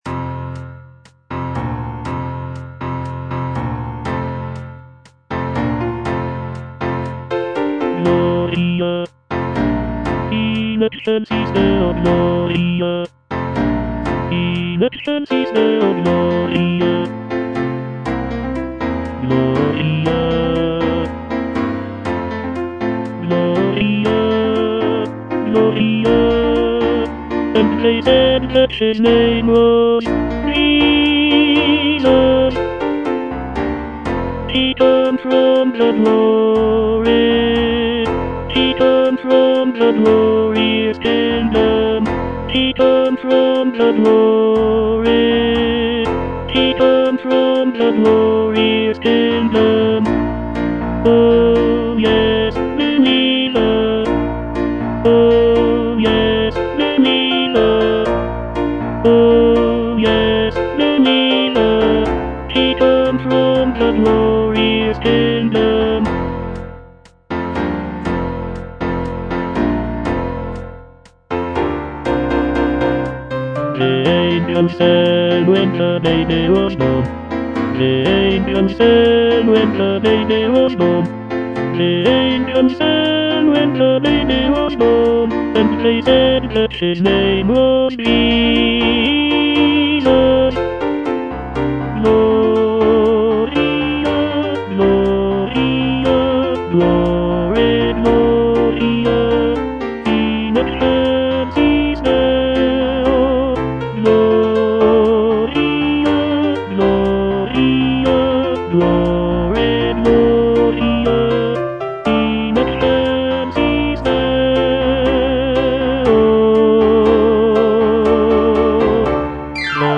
Tenor (Voice with metronome)
" set to a lively calypso rhythm.